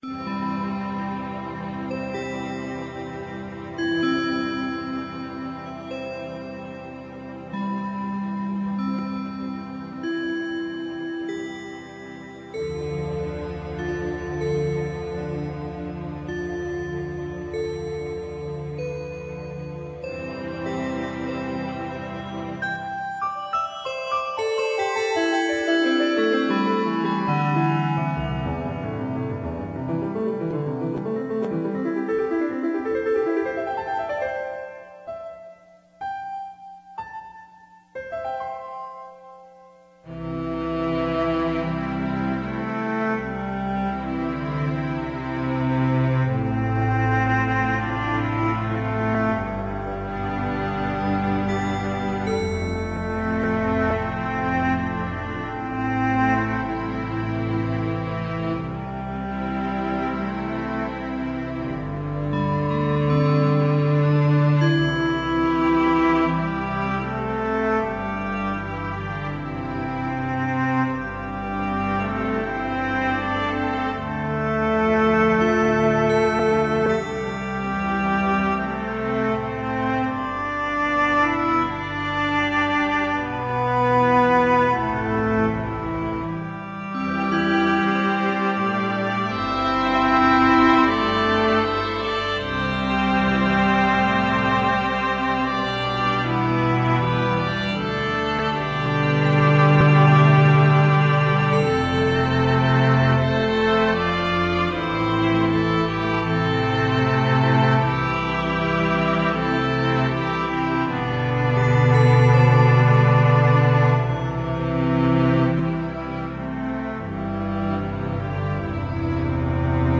FLDFGroupPerformance_256k.ra